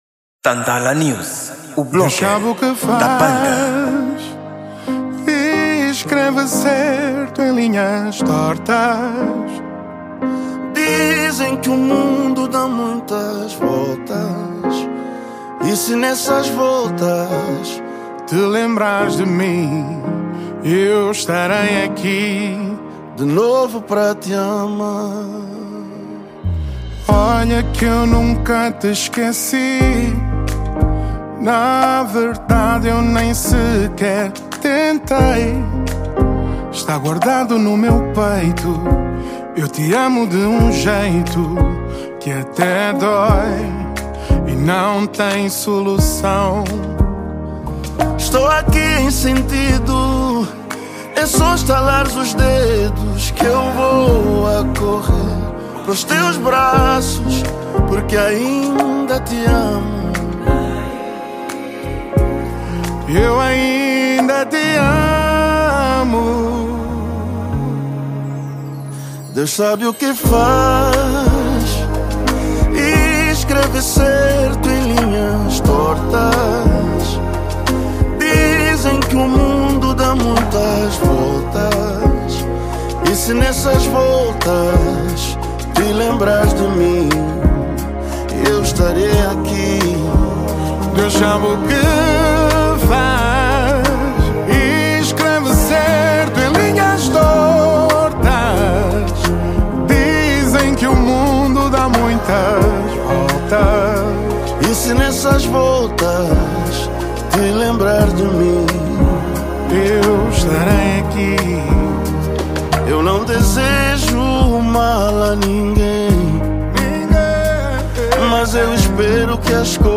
Gênero: Soul